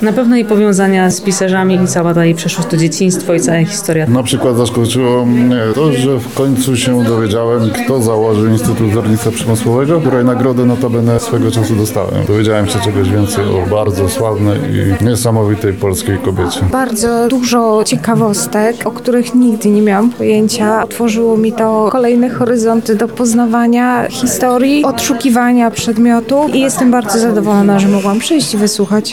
Wydarzenie odbyło się w galerii LubVintage.
Uczestnicy spotkania byli pod wrażeniem wysłuchanej prezentacji. Podzielili się tym, co ich najbardziej zainteresowało, a co nawet zaskoczyło:
Opinie słuchaczy